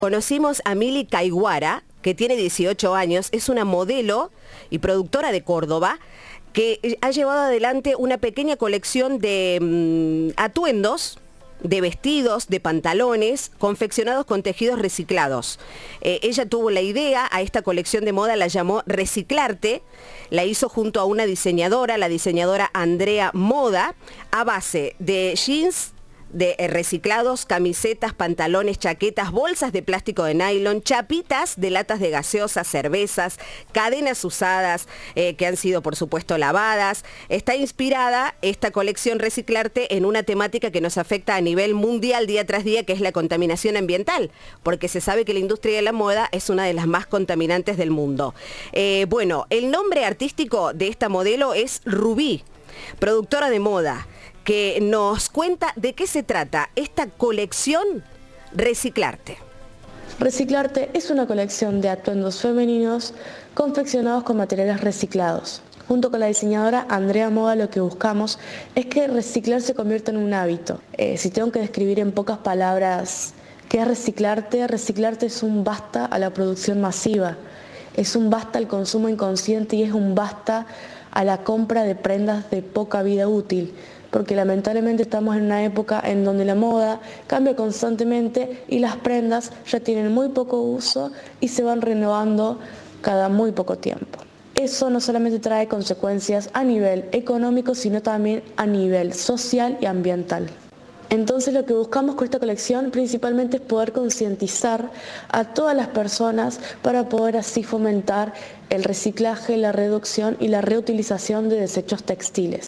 En diálogo con Cadena 3, Raúl La Cava, Secretario de Políticas Sociales, Inclusión y Convivencia, celebró la propuesta y señaló la importancia de la Economía Circular.
Informe